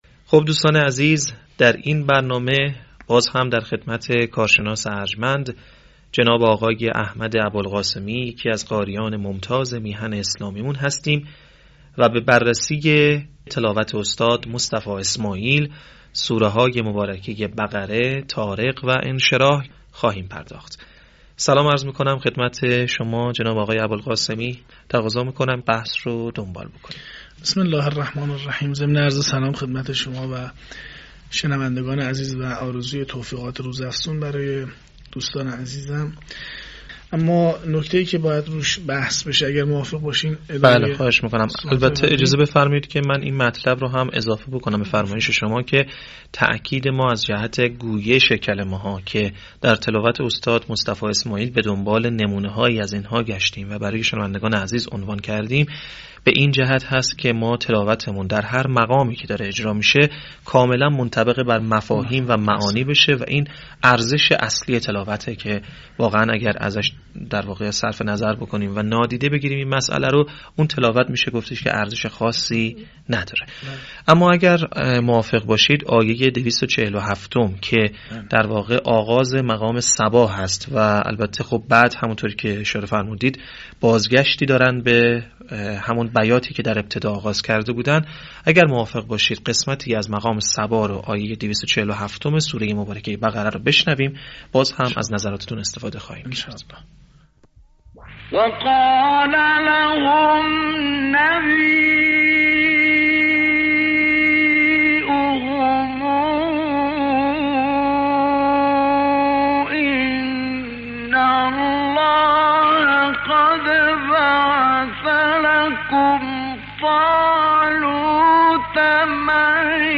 صوت | تحلیل تلاوت «مصطفی اسماعیل» از سوره بقره، طارق و انشراح